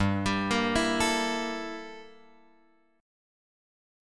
Gm6add9 chord {3 x 5 3 5 5} chord